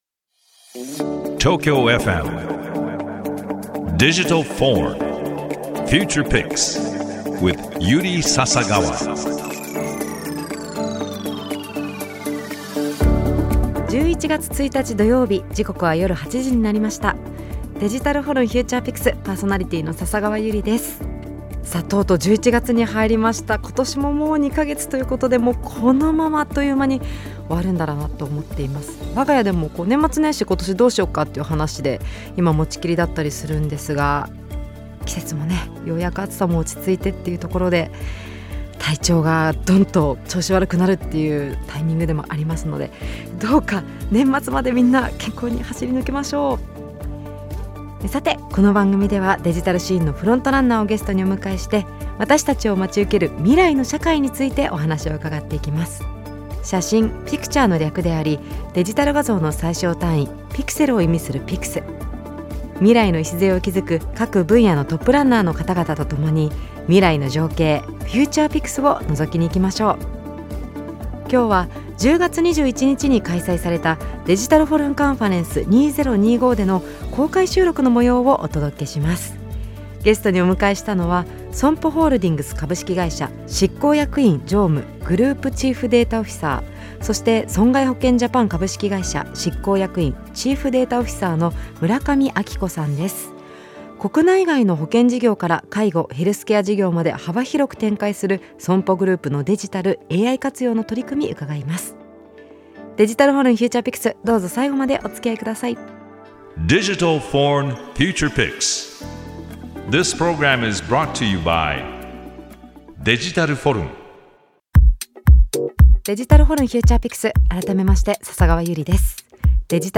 今回は前回に引き続き、10月21日に開催された 「デジタルフォルンカンファレンス2025」での公開収録の模様をお届けします。